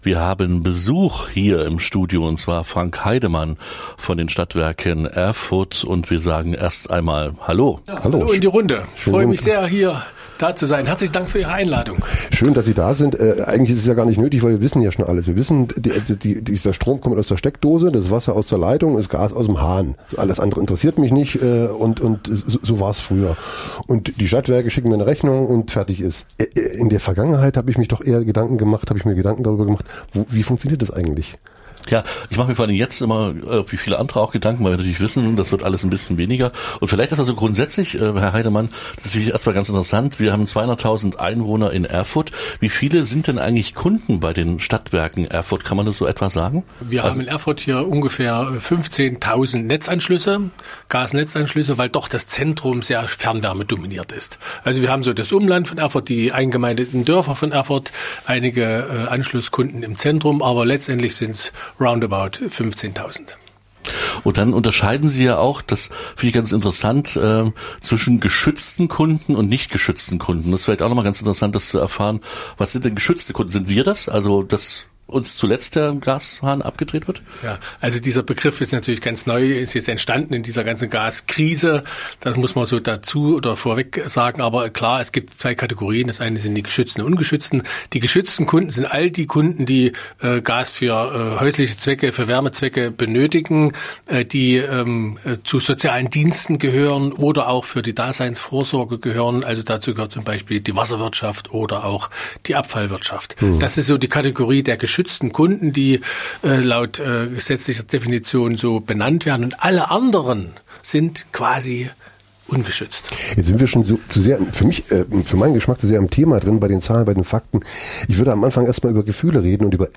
Zwei Dullis fragen trotzdem nach.